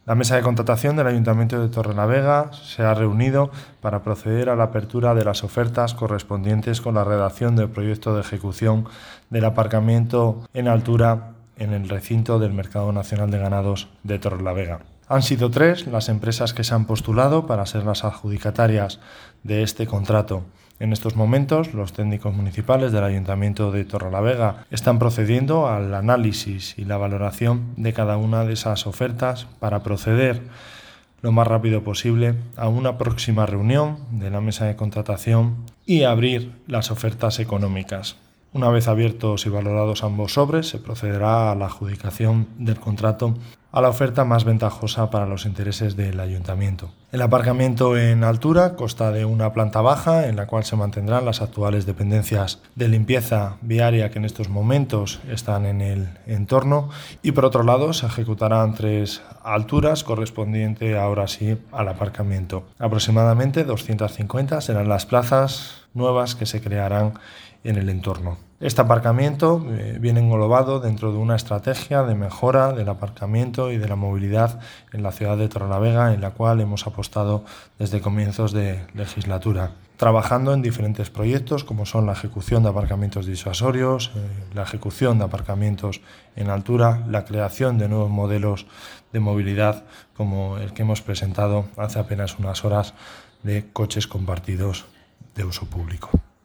Audio de Javier López Estrada